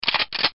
被敌人发现 敌人上子弹.mp3